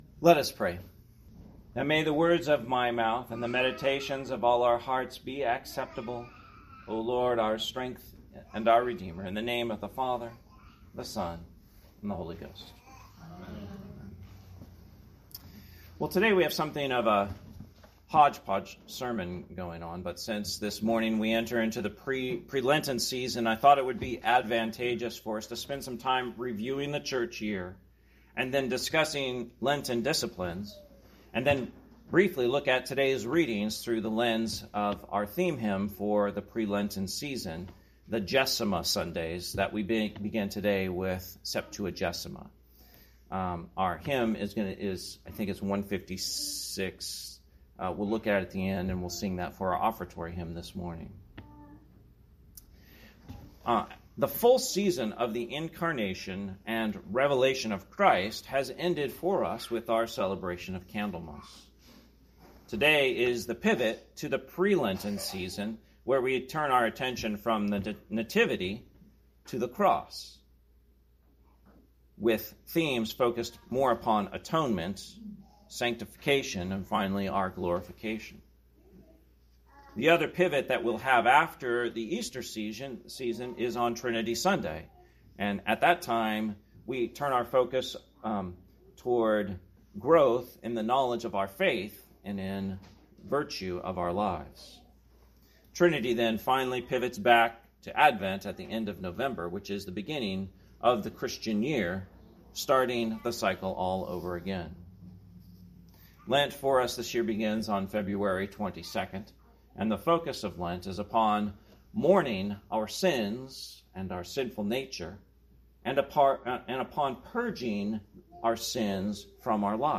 Sermon, Septuagesima Sunday, 2023